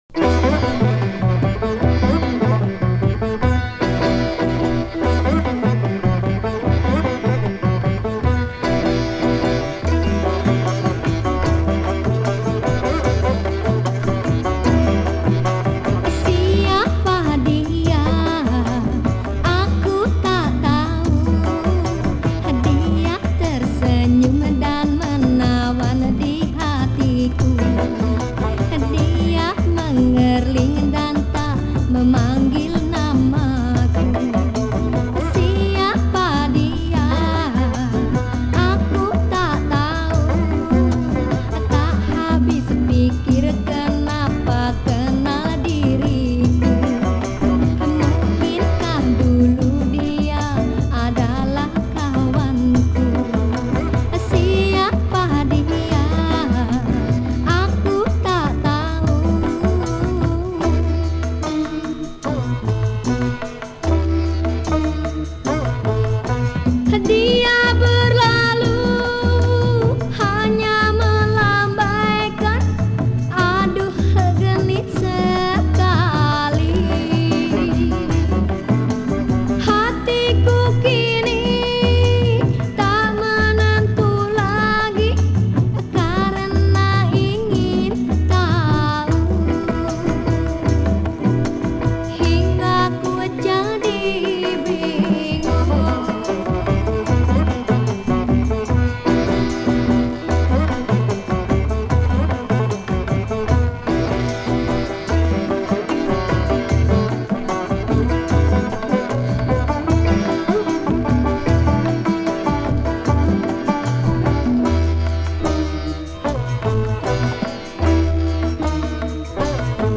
pop singer
guitarist